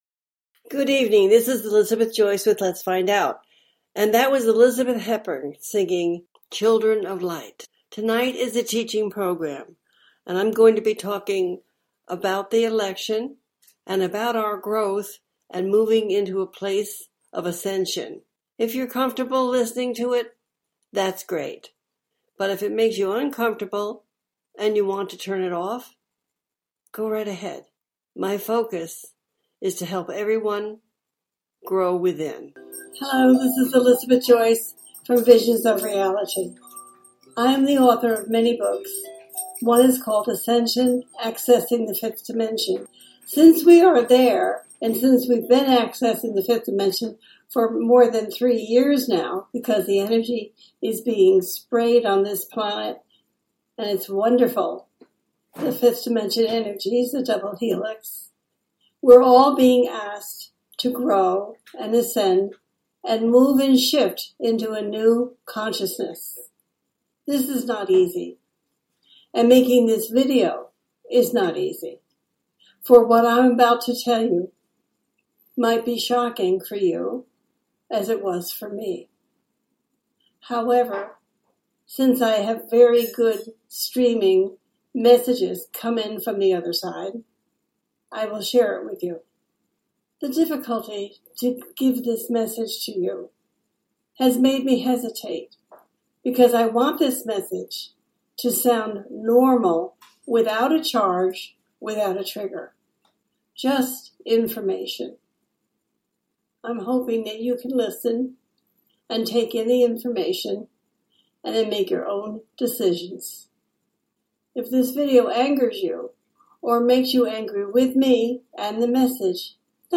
The listener can call in to ask a question on the air.
Each show ends with a guided meditation.
Talk Show